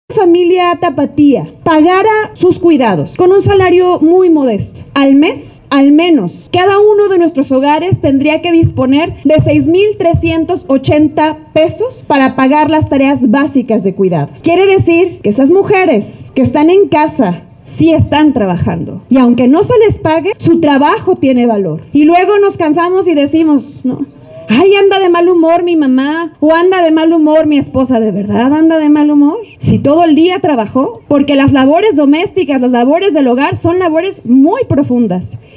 En Guadalajara hay 272 mil niñas y niños y 168mil adultos mayores que necesitan de cuidados para vivir de cada 10 mujeres que cuidan 2 son adultas mayores y 6 de cada 10 tienen entre 30 y y 59 años una edad altamente productiva habla la presidenta Verónica Delgadillo